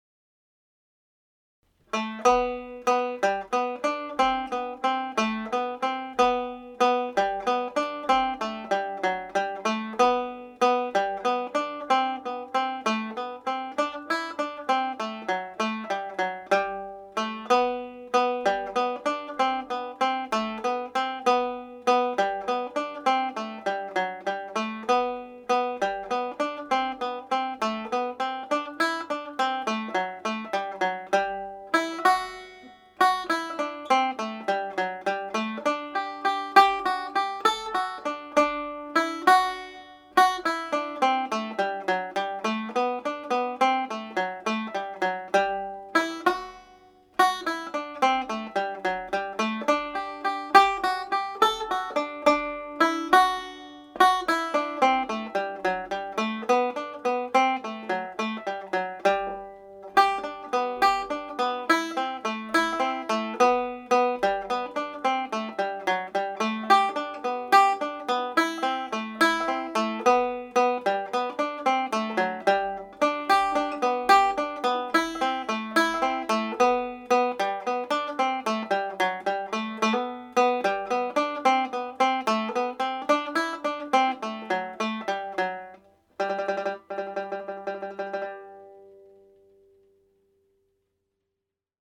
The Humours of Ennistymon played slowly
Humours-of-Ennistymon-slow.mp3